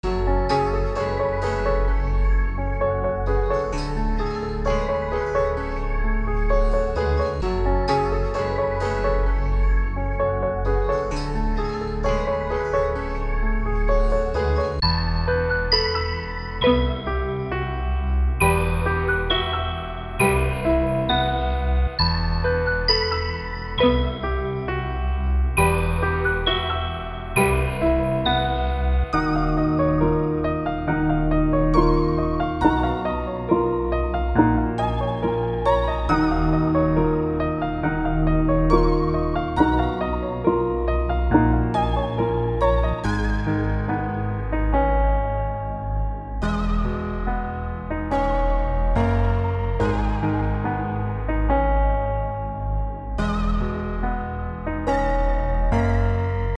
该套件的风格从Jaques & Dej Loaf到Chris Brown & Quavo。